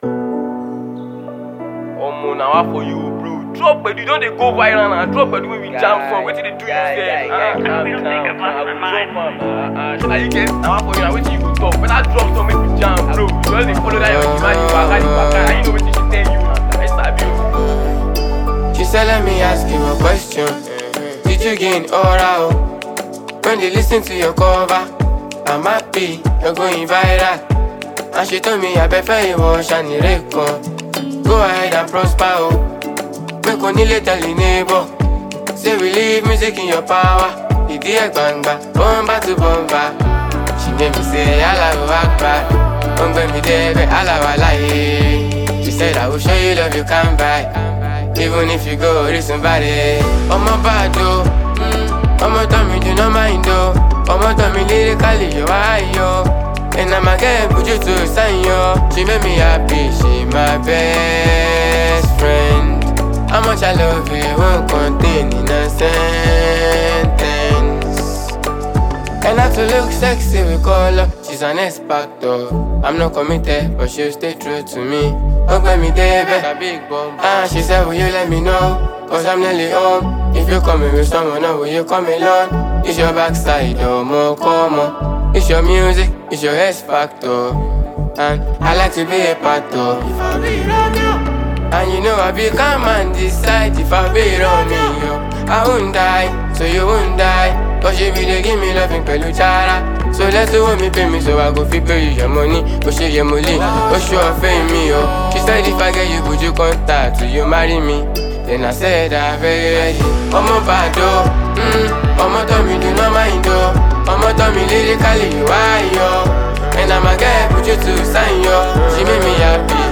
modern Afrobeats sensibilities